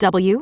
OCEFIAudio_en_LetterW.wav